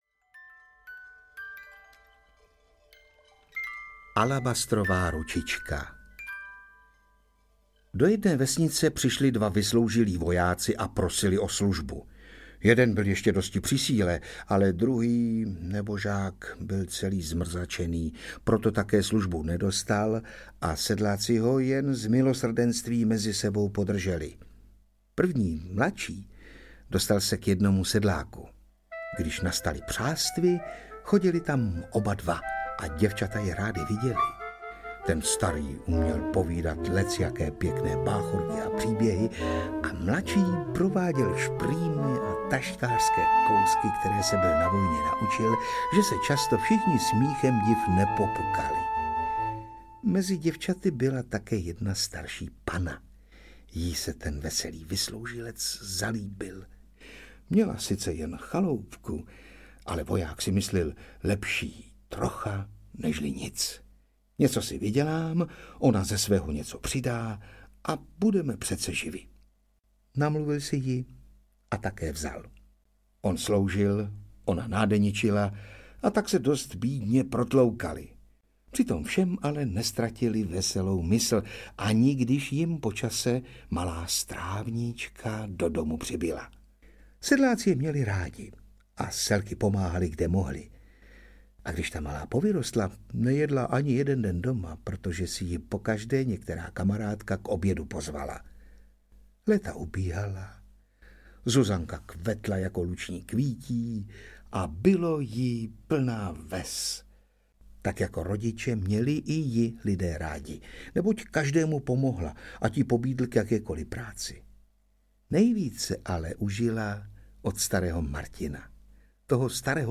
Ukázka z knihy
pohadkovy-mlynek-audiokniha